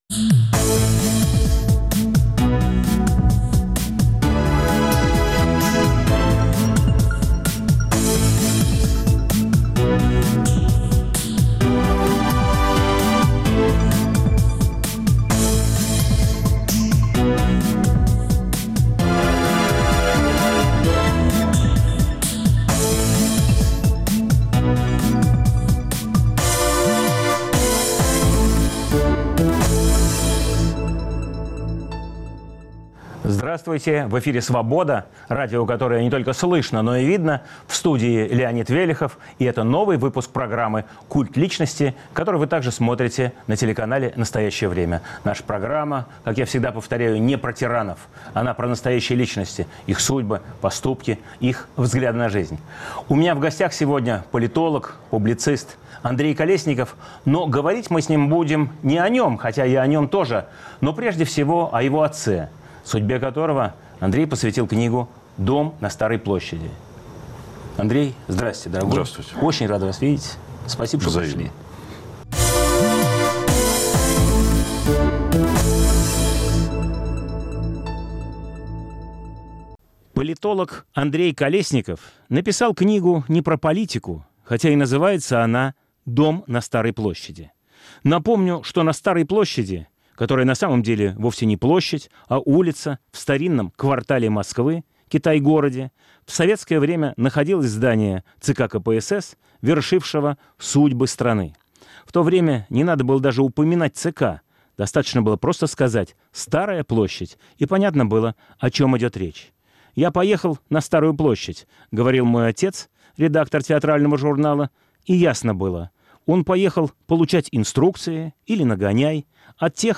В студии программы – журналист Андрей Колесников с его новой книгой «Дом на Старой площади».